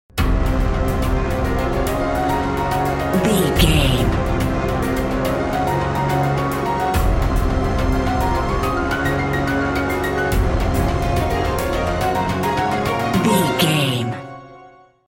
Fast paced
Phrygian
chaotic
intense
percussion
piano
strings
horns
brass